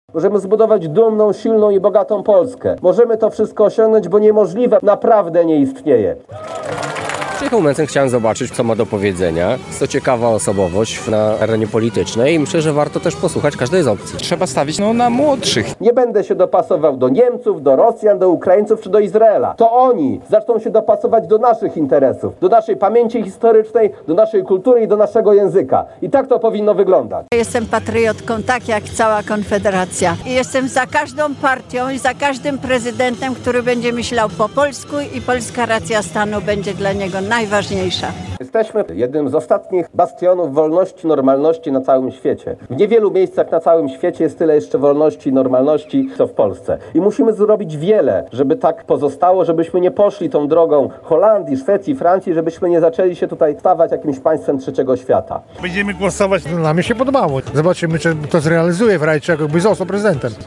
W spotkaniu na Placu Marii Konopnickiej wzięło udział ponad pół tysiąca mieszkańców.
relacja